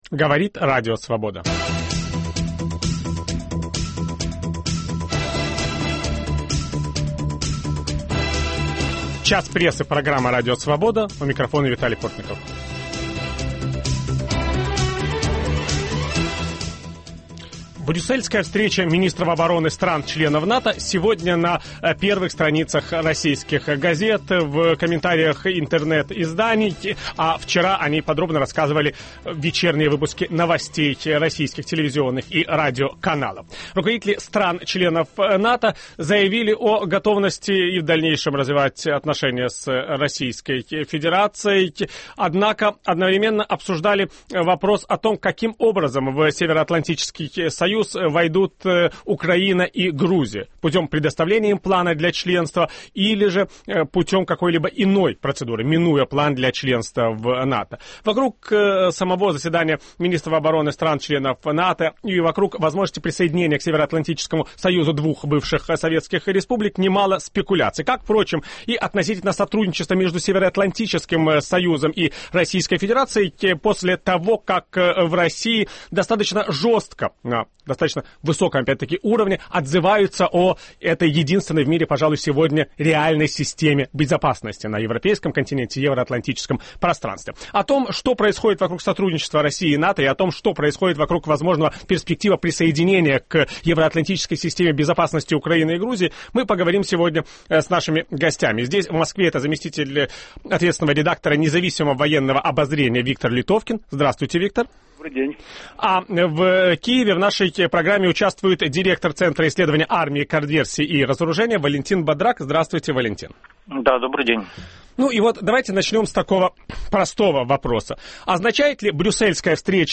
О евроатлантических перспективах Украины и Грузии после встречи министров обороны стран НАТО ведущий программы Виталий Портников беседует с военными обозревателями.